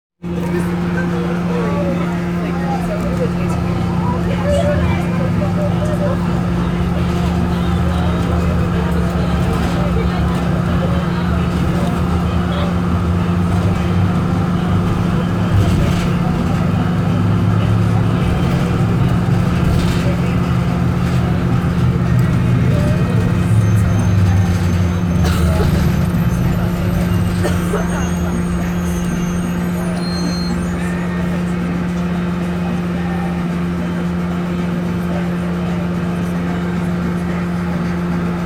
Field Recording 5
Location/Time: On the shuttle around Stop & Shop, 6:15 p.m.
Sounds Heard: heat blasting, people talking, something metal banging around the bus, screeching breaks
Bus-Ride-2-screeching-brakes.mp3